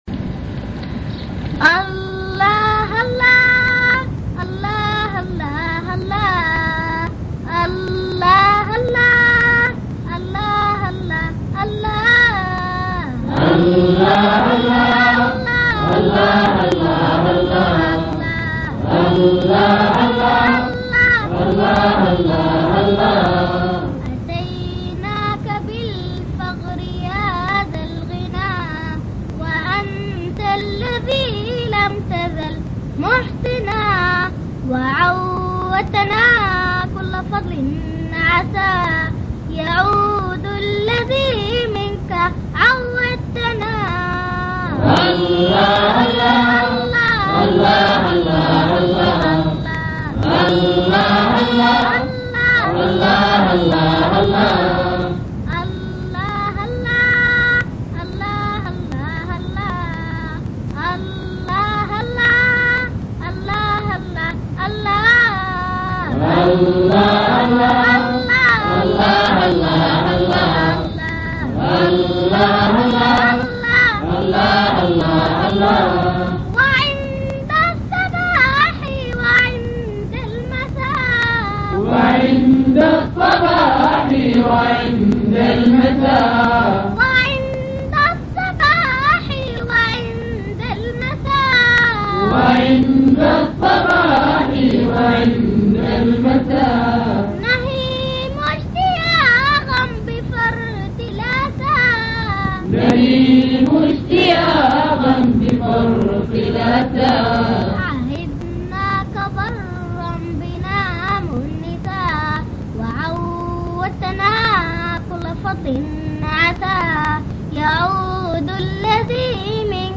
إنشاد
القصائد العرفانية